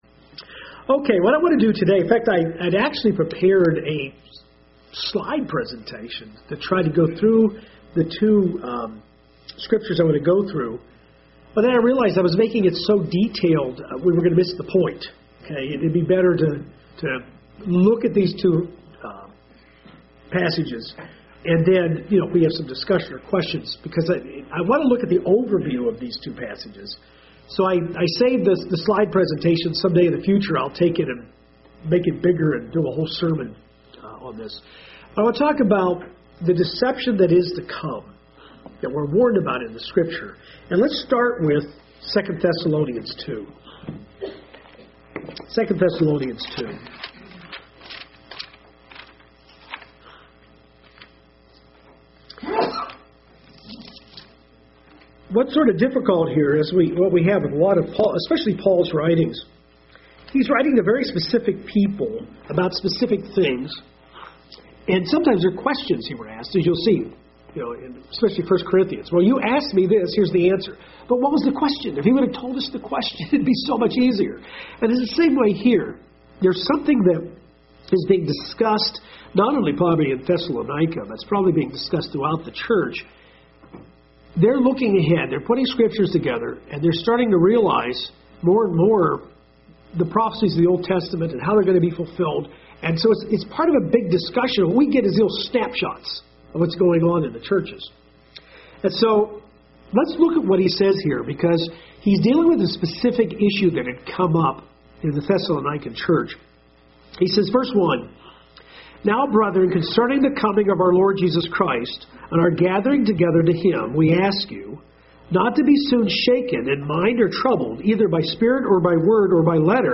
This Bible Study covers the "great deception" covered by the Apostle Paul in 2 Thessolonians. This is tied into the prophetic timeline laid out in Revelation 13.